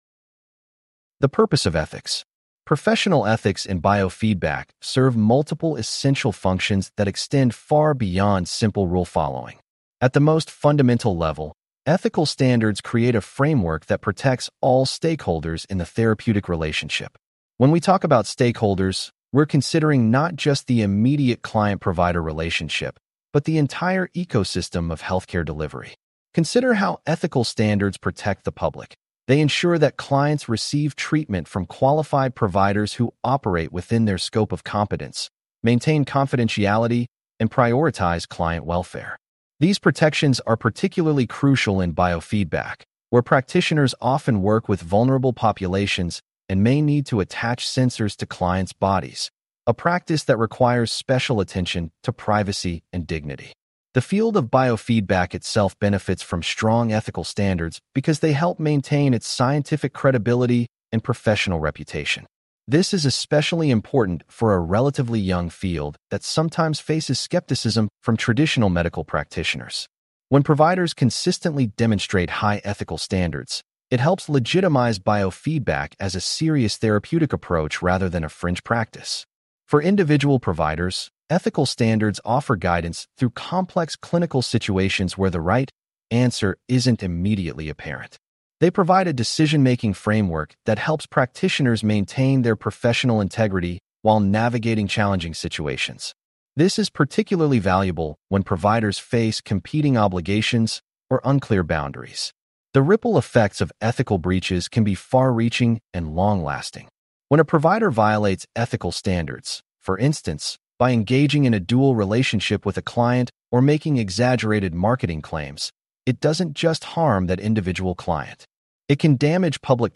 Please click on the podcast icon below to hear a full-length lecture.